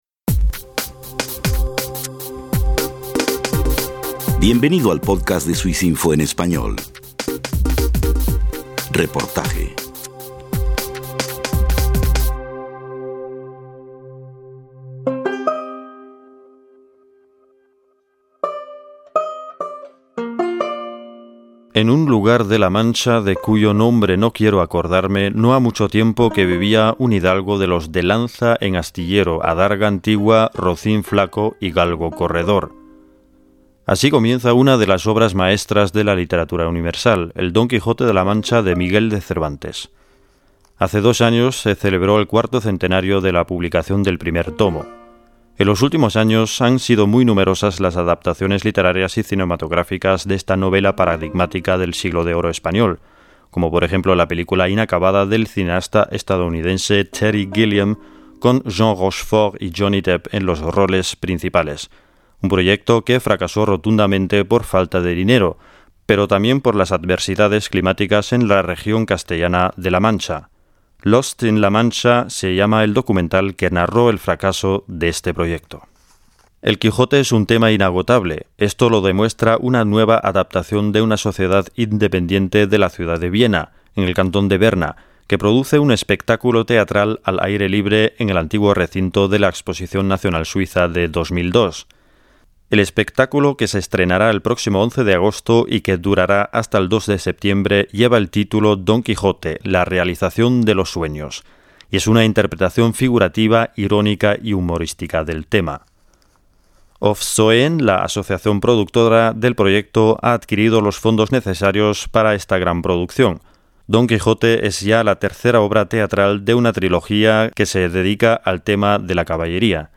Archivo MP3 En plena temporada estival, se estrena este lunes en la ciudad de Biena en el cantón de Berna una obra teatral que retoma el tema del célebre caballero andante de la Mancha. El espectáculo es plurilingüe y se escenificia en un antiguo recinto de la exposición nacional suiza de 2002. Un reportaje de